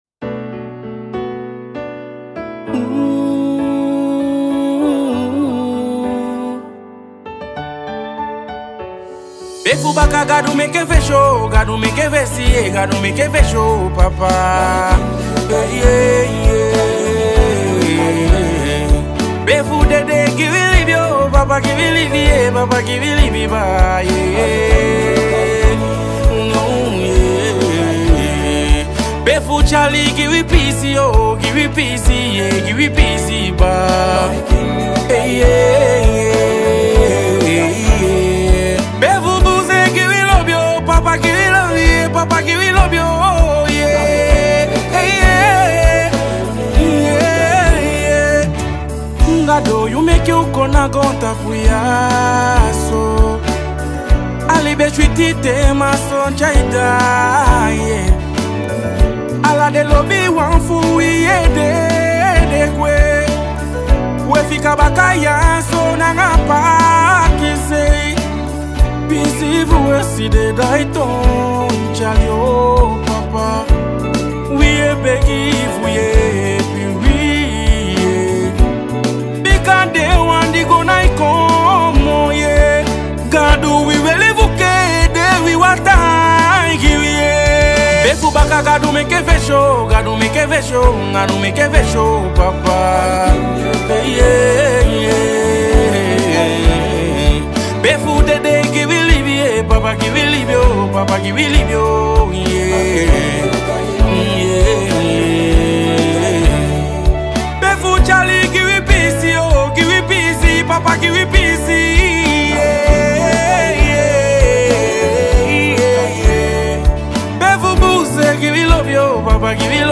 Universal Gospel